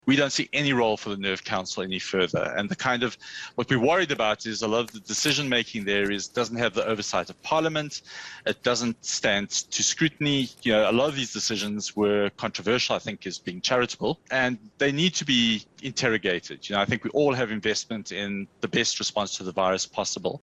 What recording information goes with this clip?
spoke to eNCA and had this to say.